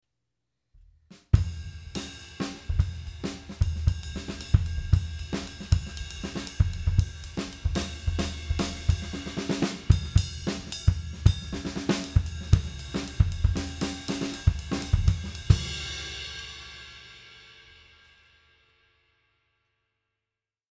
• It is not dry
• It is a good sounding ride, if you are not expecting ‘dry’ or ‘light’
I think is is a pretty decent rock cymbal, with a strong bell, and a bit of wash.